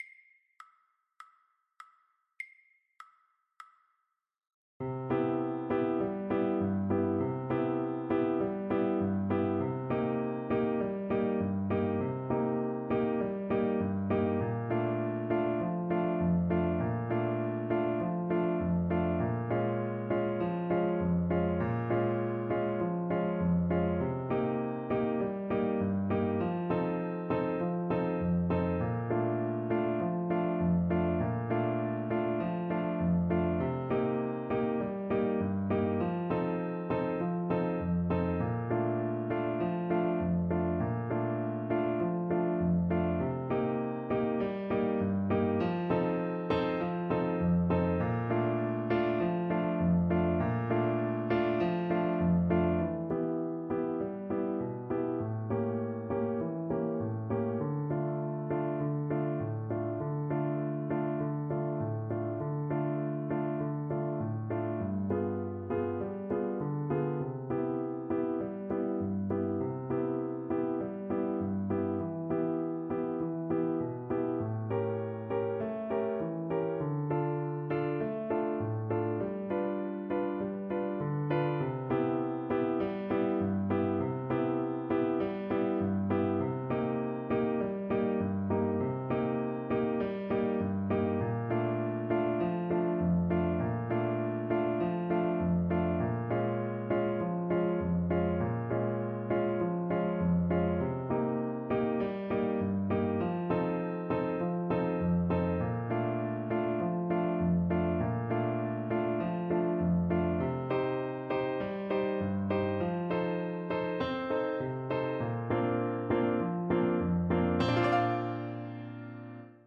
Arrangement for Trombone and Piano
4/4 (View more 4/4 Music)